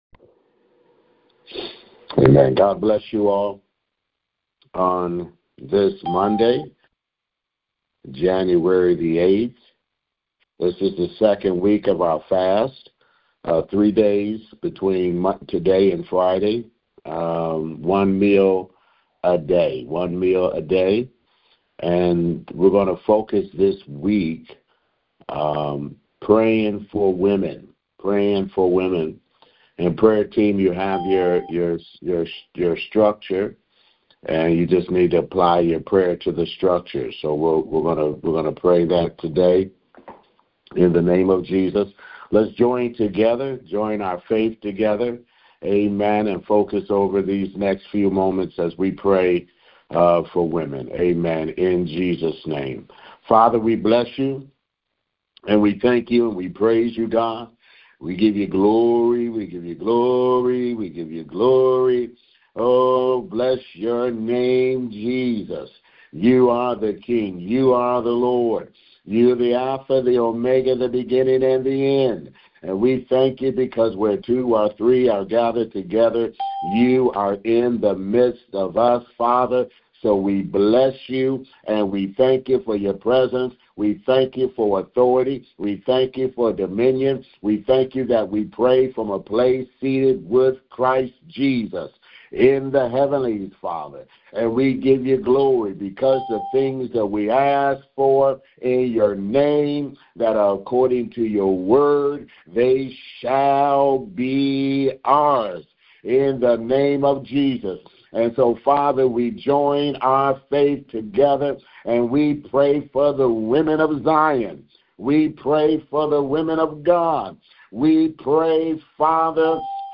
Prayers for women taken from the weekly prayer conference line.